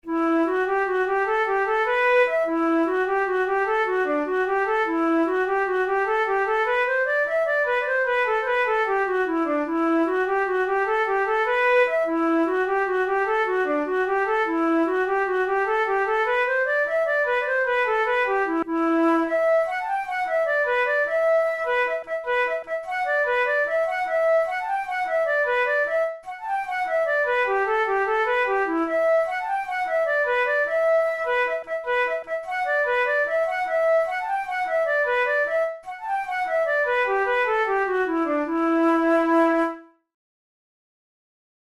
InstrumentationFlute solo
KeyE minor
Time signature6/8
Tempo100 BPM
Jigs, Traditional/Folk
Traditional Irish jig